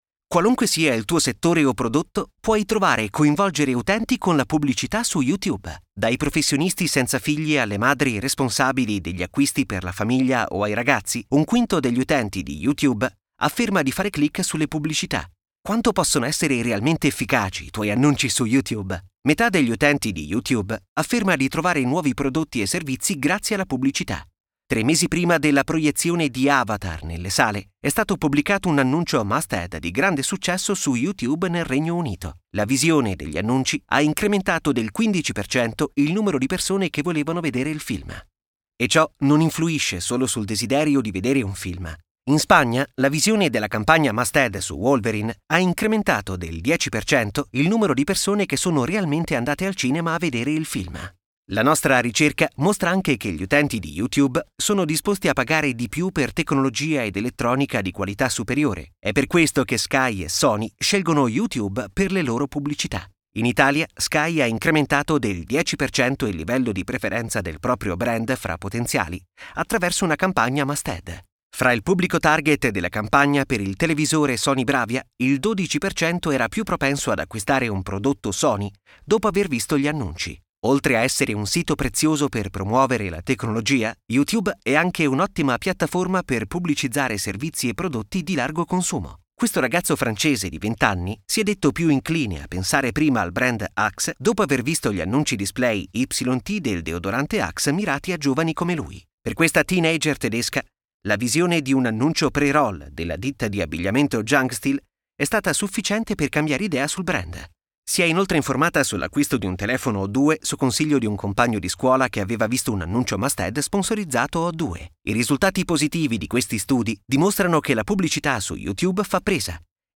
Sprecher italienisch.
Sprechprobe: Werbung (Muttersprache):